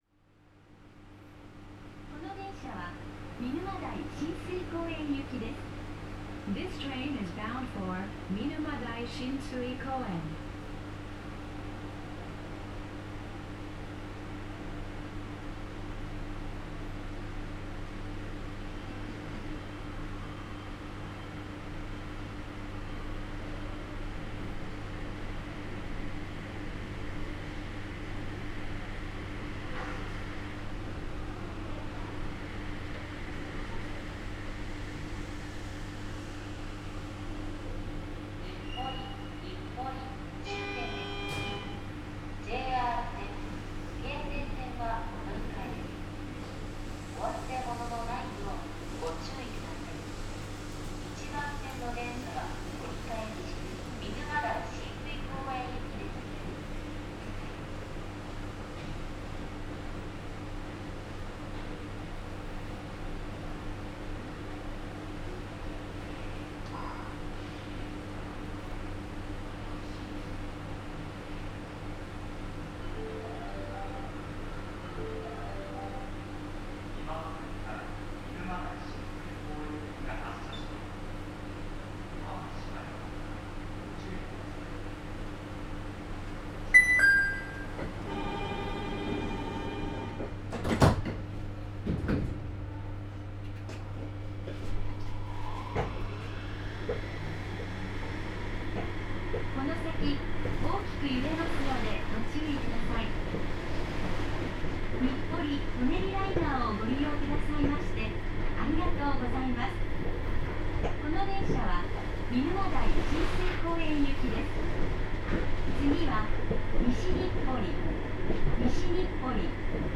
・走行音(全区間)(その2)(130MB★)←new!!!←2024年収録
三菱のSiC素子採用車両は非同期音が「シュー」という感じの一定音の車両が多いが、この車両は都営では6300形後期車のように音程が変化するタイプである。全閉形主電動機を採用していることもあり高速域の音は5300形と比較すると大幅に小さくなっている。ドア開閉音やブレーキ緩解音はJRE235系とほぼ同じもので、同一品を採用しているためと思われる。空調が常時稼働しているのがかなり残念なところである。